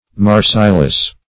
Marseilles \Mar*seilles"\, n.